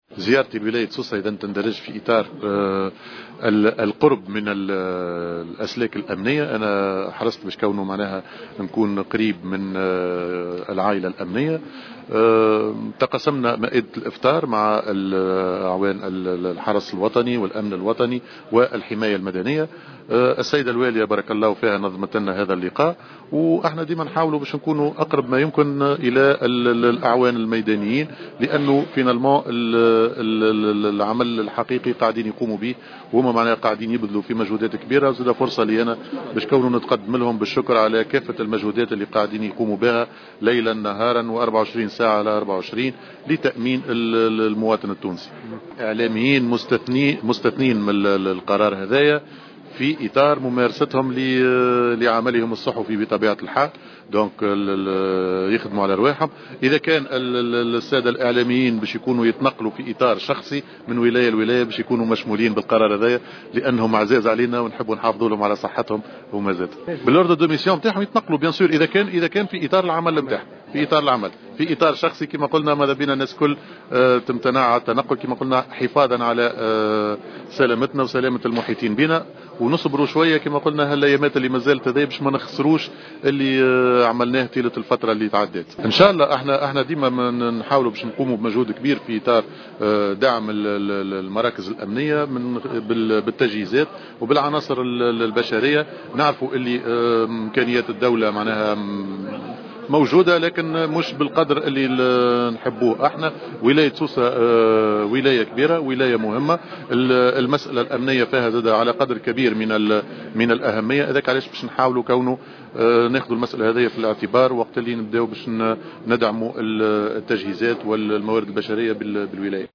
أكد وزير الداخلية هشام المشيشي في تصريح للجوهرة أف أم، أن الإعلاميين والصحفيين لا يشملهم القرار المتعلق بمنع التنقلات بين الولايات خلال أيام عيد الفطر، وسيكون بإمكانهم التنقل في إطار ممارستهم لمهامهم بين المدن والولايات مع الاستظهار بوثيقة التكليف بمهمة.